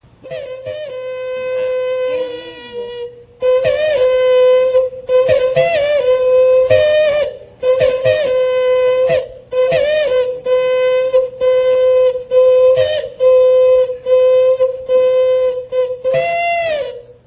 Clarinete heteróglota
Indígena Warao.
Aerófono, de soplo, de lengüeta. Clarinete, aislado, de tubo cilíndrico.
Para ejecutarlo, se introduce el cilindro dentro de la boca librando la lengüeta de obstrucción, la cual vibra con la emisión gradual del soplo hasta producir el sonido.
Grabación: Toque Ceremonial
Característica: Toque propio de la ceremonia ritual denominada Najanamu
Procedencia, año: Barranquilla, Yuara Acojo, Caño Güiniquina, estado Delta Amacuro, Venezuela, 1978